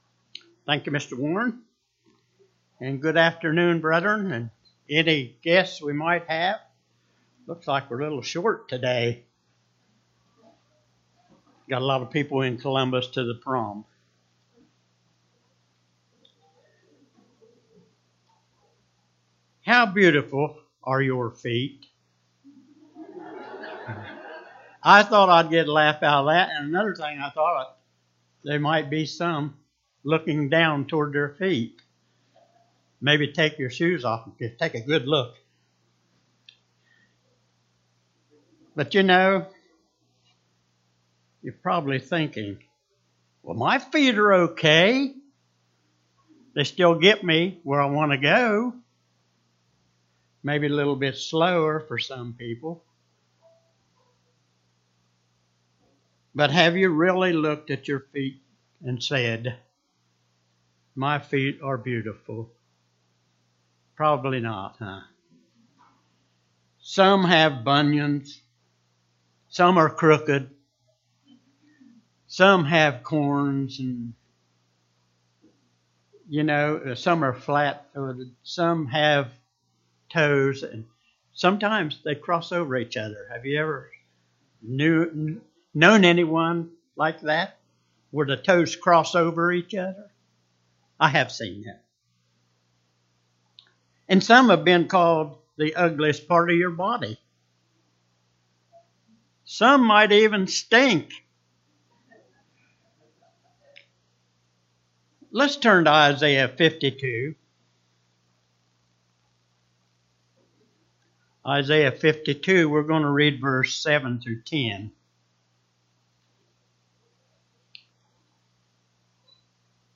Sermons
Given in Portsmouth, OH